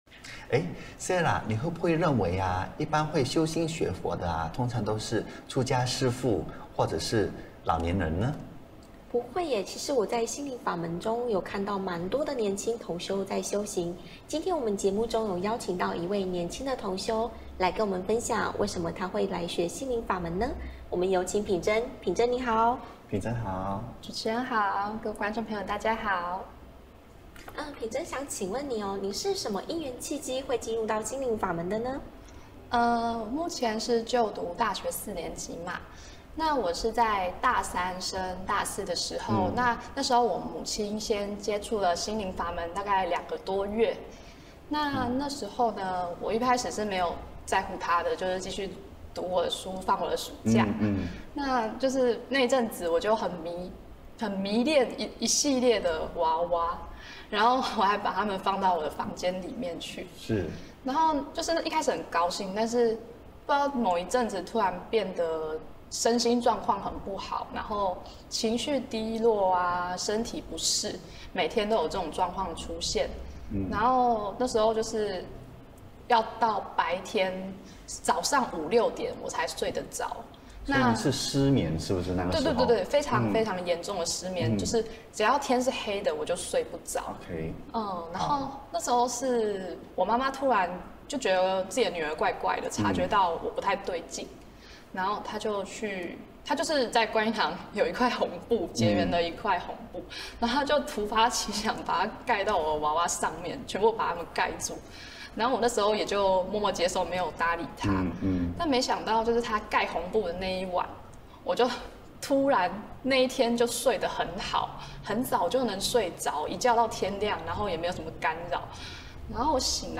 视频：18.佛學會訪談【訪談分享】02 - 新闻报道 心灵净土